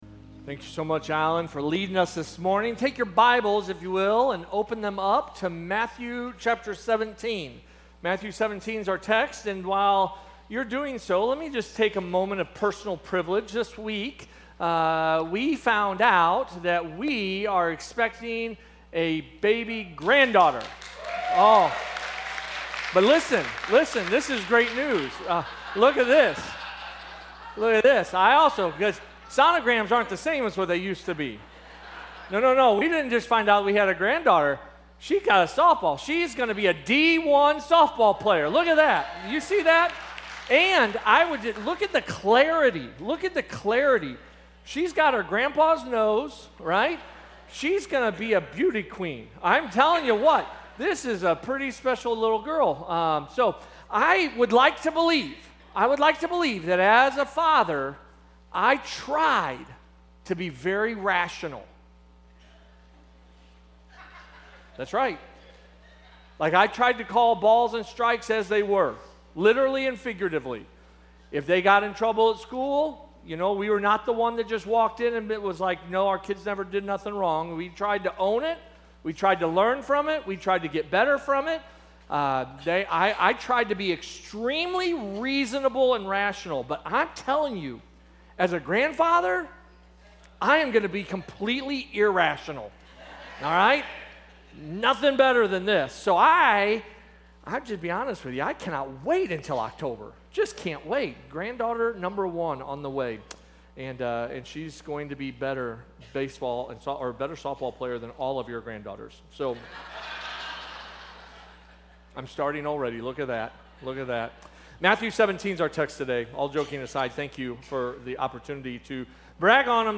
A message from the series "Lord Teach Us To Pray."